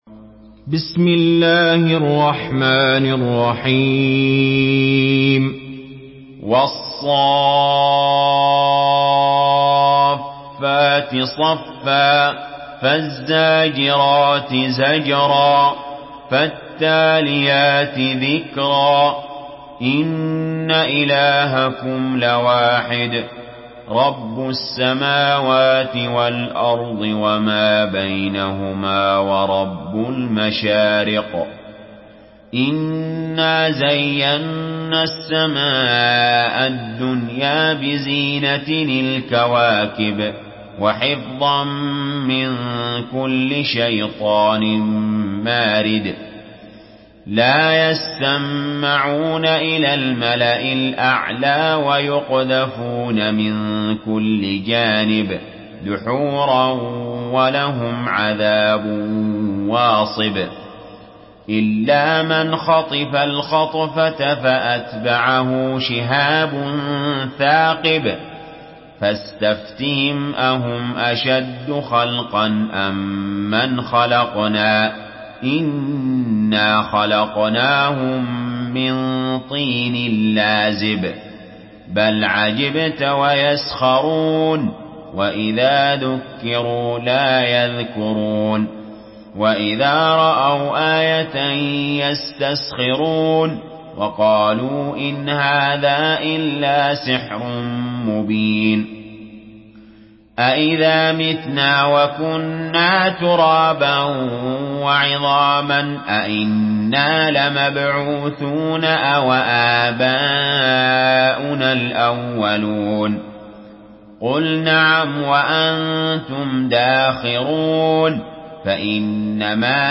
Surah الصافات MP3 by علي جابر in حفص عن عاصم narration.
مرتل